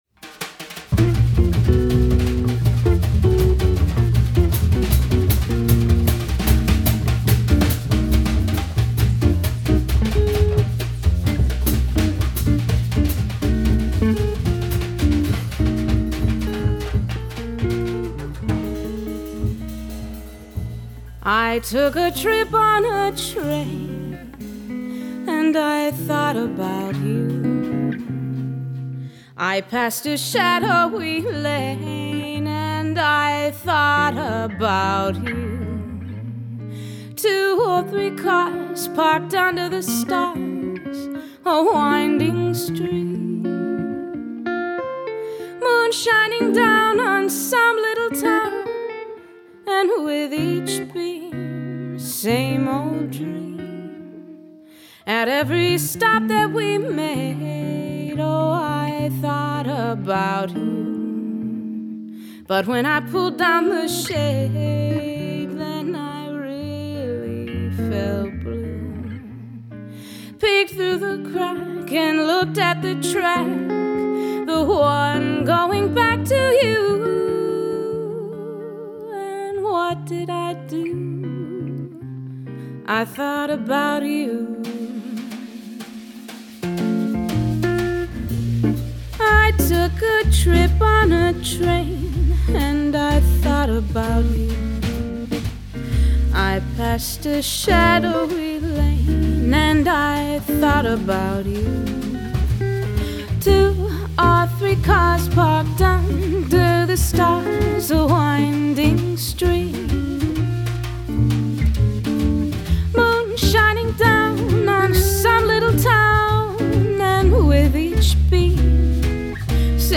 • Guitarister
• sangerinder